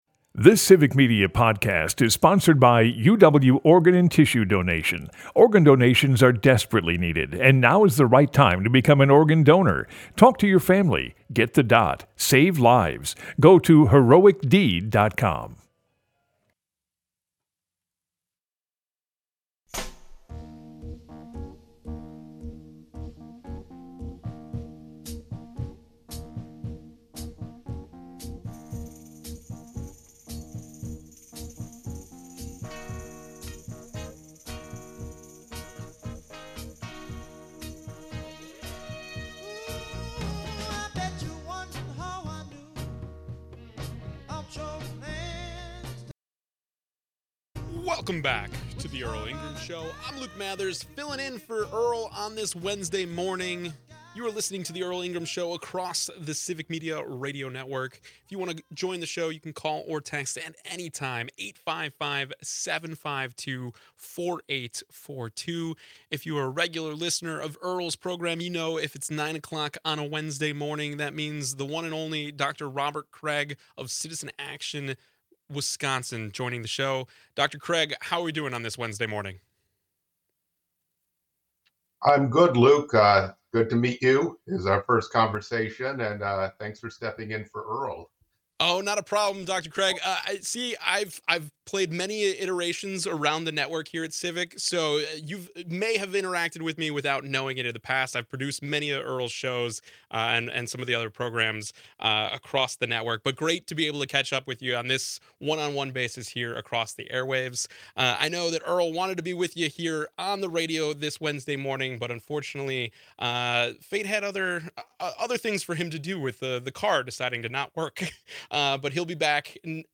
They start off the hour talking about the slew of executive orders signed by Donald Trump in the first days of his administration and the illegality of many of them. Then, they discuss how Citizen Action is organizing for Wisconsin's spring election before opening up the conversation to callers.